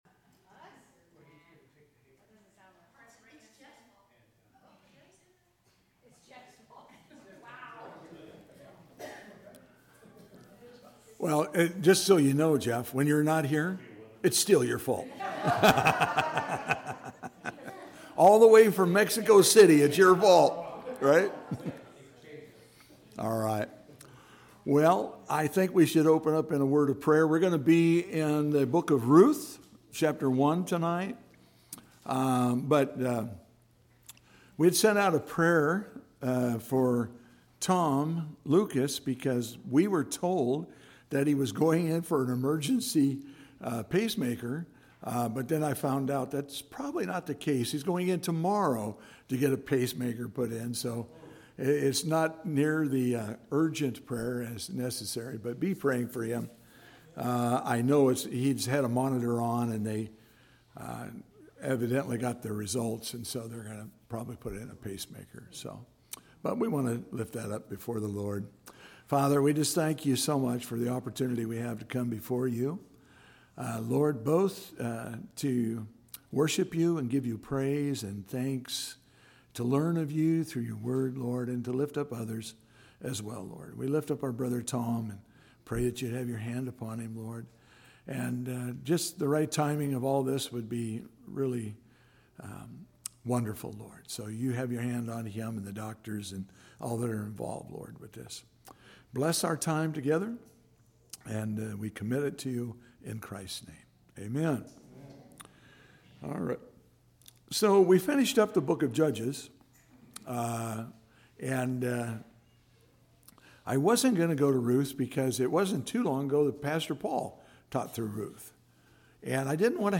CCS Sermons